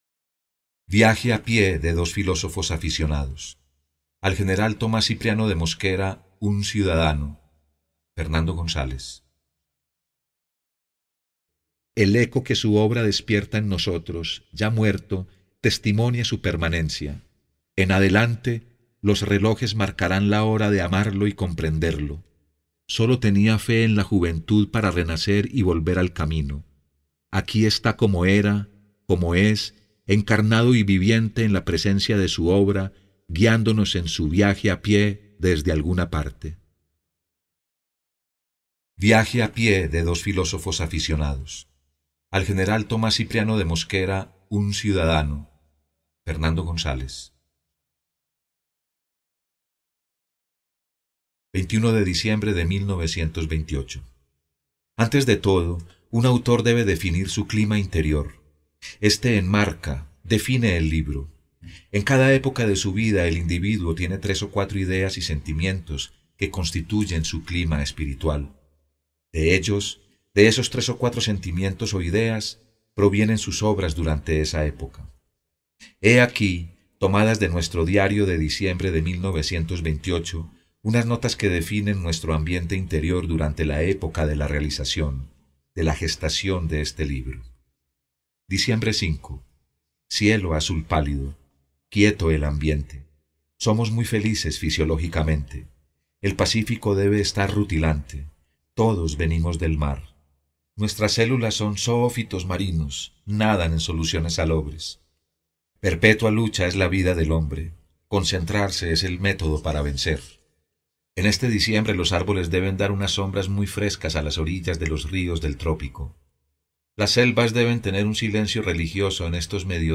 Masculino
AudioBook-fragmento
Voz Padrão - Grave 02:12